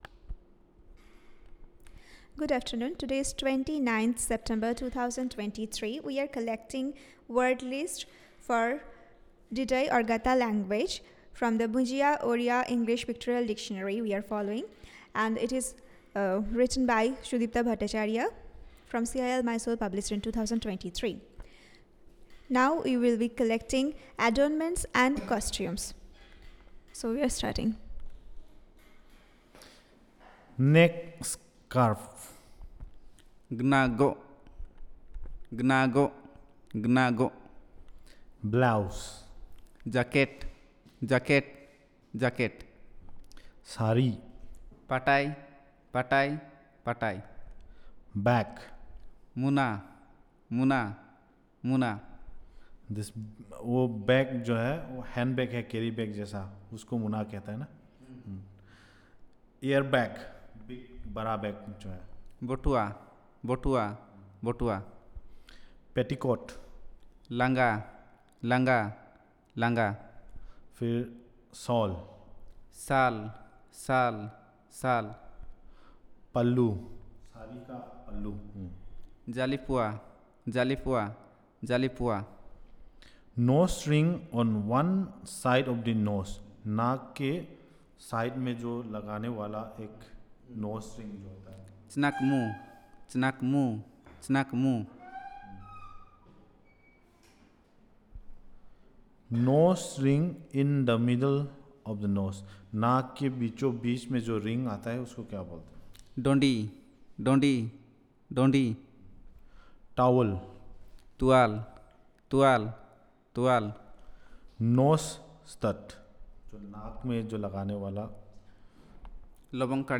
Elicitation of words on adornments and costumes